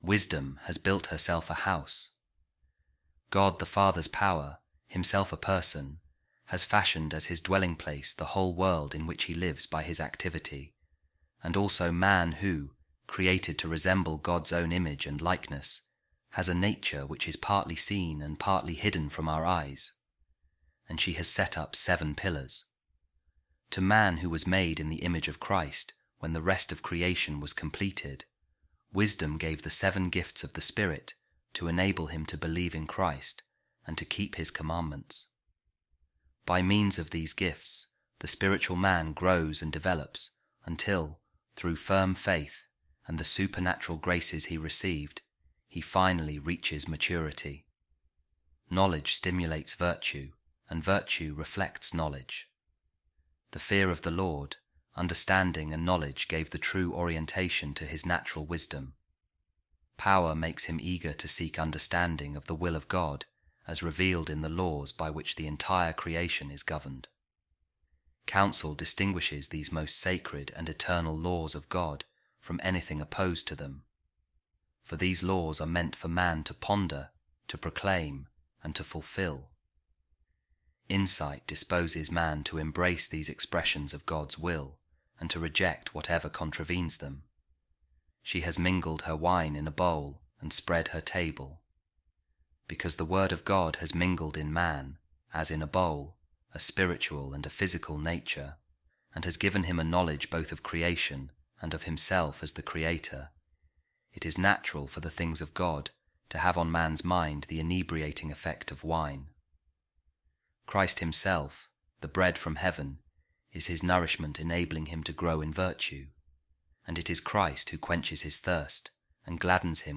Office Of Readings | Wednesday, Week 6, Ordinary Time | A Reading From A Commentary On The Book Of Proverbs By Saint Procopius Of Gaza | Wisdom And Foolishness